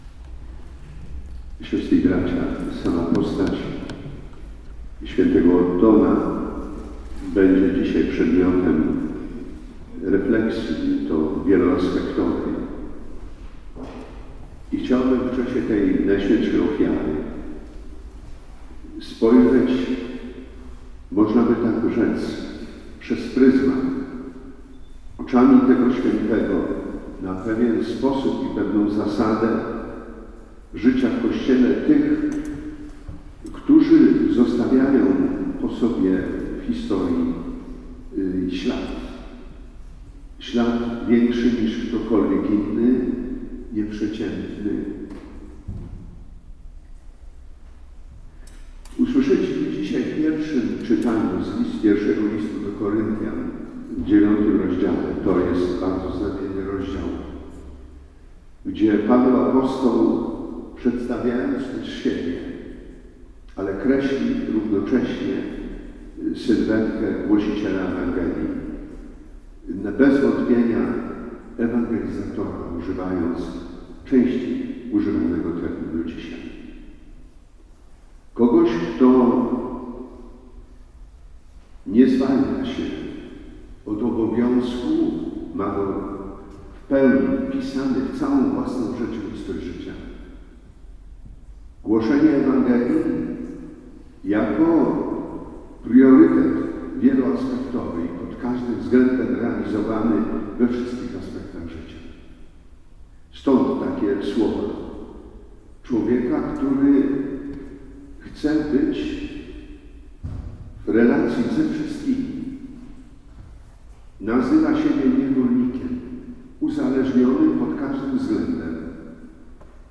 4 grudnia na Akademii Pomorskiej w Słupsku odbyło się sympozjum poświęcone św. Ottonowi z Bambergu, Apostołowi Pomorza. Wykłady poprzedziła Msza św. pod przewodnictwem bp. Edwarda Dajczaka w kościele pw. św. Ottona.
Bp Edward Dajczak o tym, do czego powinien być zdolny prawdziwy ewangelizator - homilia
Zapraszamy do wysłuchania homilii, w której biskup kreśli sylwetkę współczesnego ewangelizatora: